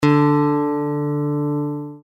Use the audio tones below to tune your guitar to an Open G Tuning (commonly used for playing slide).
D String